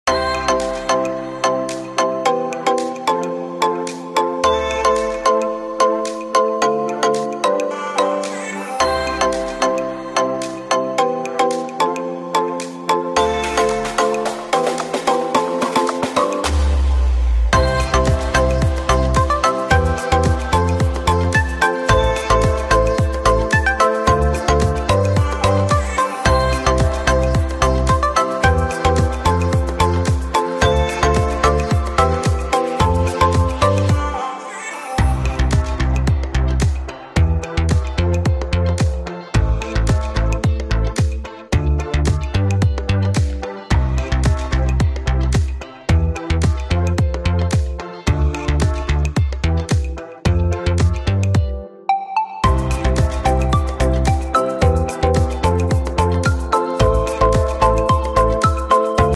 Music Track for your videos